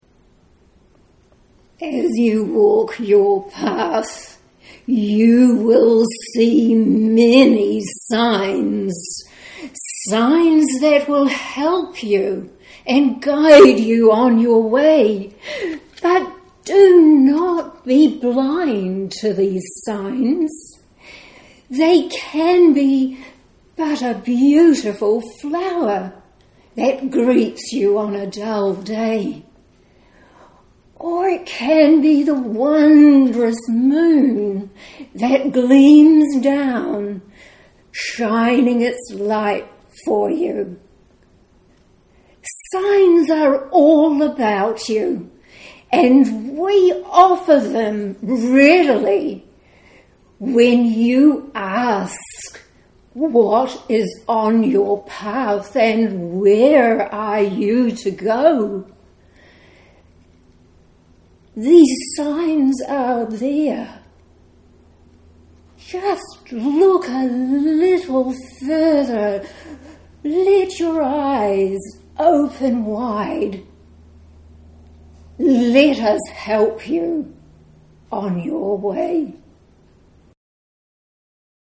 Bear in mind that it is a live recording and please excuse the deficiencies in sound quality.